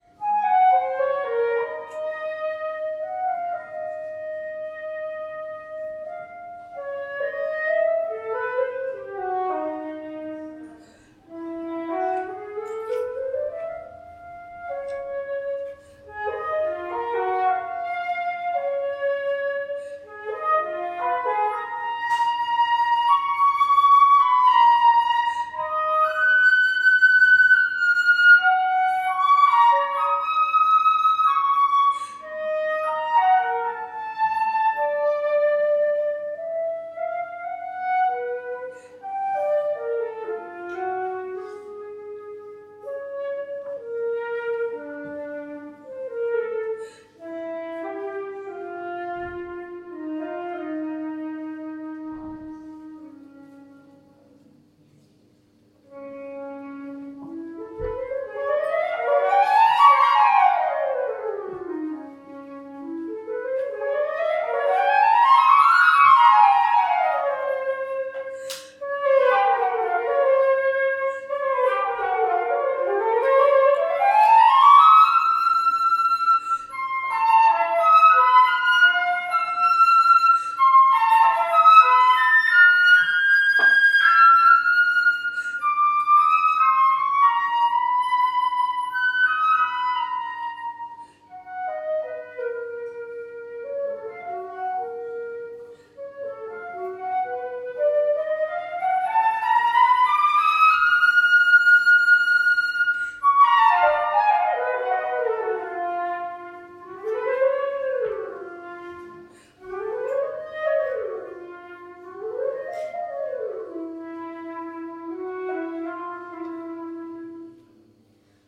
Solo
Extrait lors de la visite contée & concert, Musée Vulliod Saint-Germain, Pézenas, juillet 2023